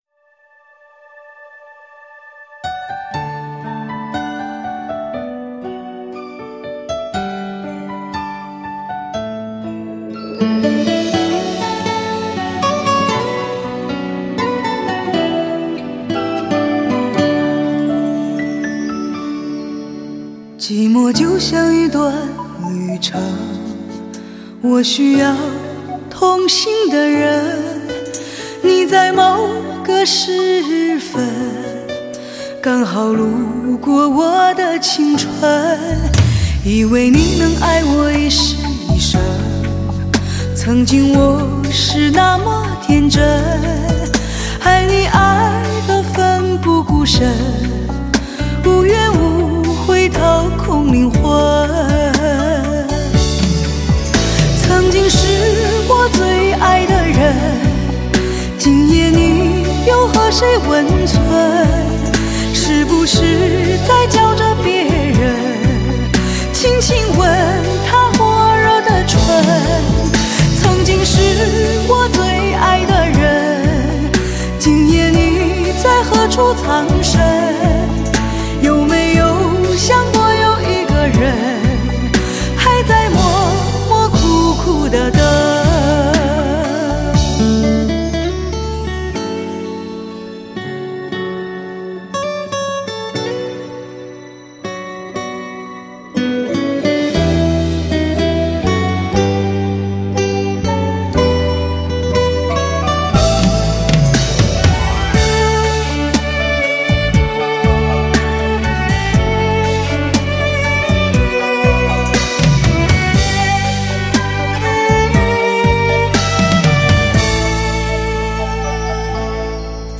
更多的是幻减后的寂静和落寞。恰如也的声音，低回婉转，哀愁如雨丝分飞，